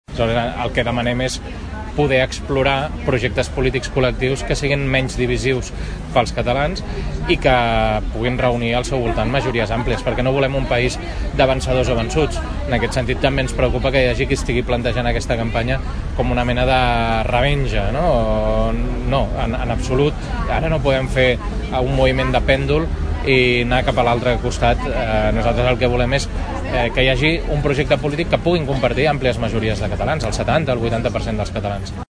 En el marc del mercat dels diumenges, el dirigent socialista va aprofitar per parlar amb el visitants amb la voluntat d’explicar els objectius del PSC per aquestes eleccions.